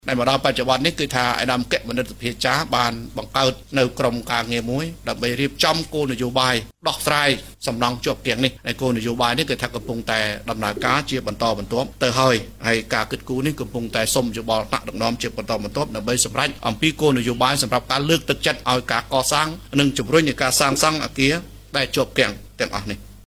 ការថ្លែងរបស់លោកអភិបាលខេត្តពីសំណង់នេះ ក្នុងសន្និសីទសារព័ត៌មាន ស្ដីពី “ភាពជោគជ័យក្នុងរយៈពេល ៥ឆ្នាំកន្លងមក” របស់រដ្ឋបាលខេត្តព្រះសីហនុ រៀបចំដោយអង្គភាពអ្នកនាំពាក្យរាជរដ្ឋាភិបាលកាលពីថ្ងៃទី១៥ ខែ កុម្ភៈ ឆ្នាំ២០២៣។